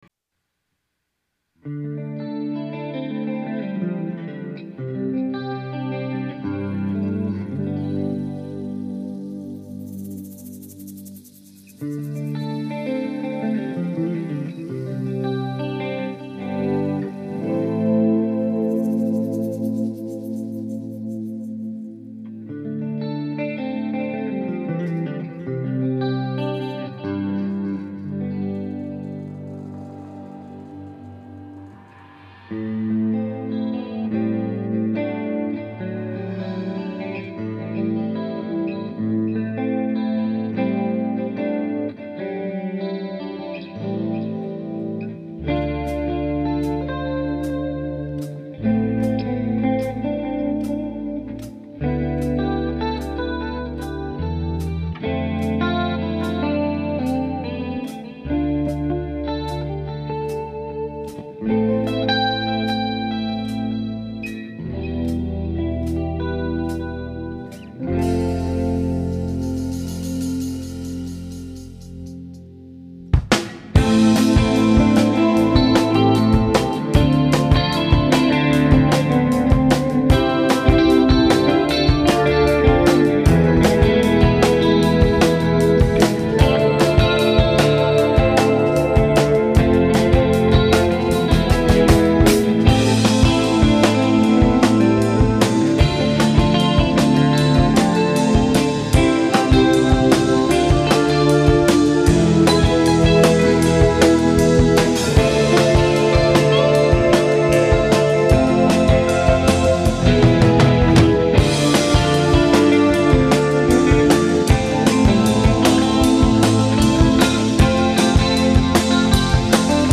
Santa Cruz home studio recordings  (1999)